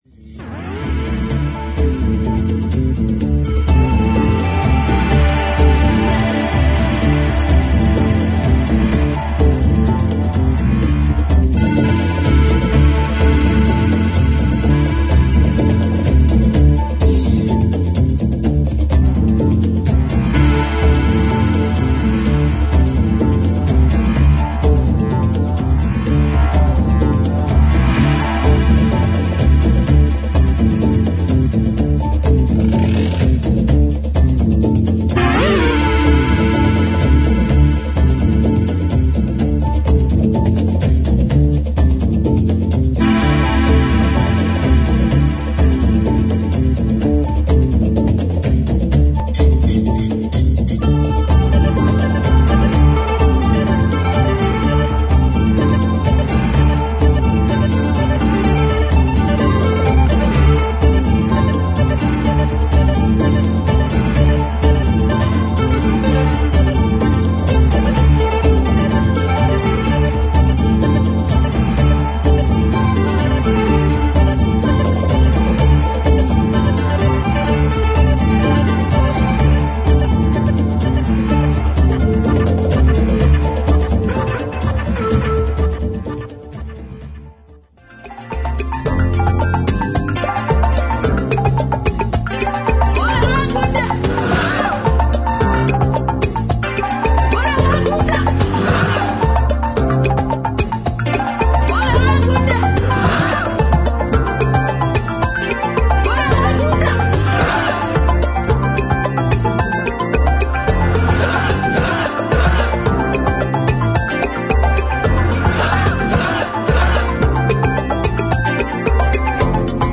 程よくトライバルなリズムと、どこかエスノな雰囲気のメロディが相まり展開する
哀愁感のあるレゲエ・フィールなバレアリック・チューン
マリンバのリフがグルーヴを牽引する、オリエンタルなダンス・ナンバー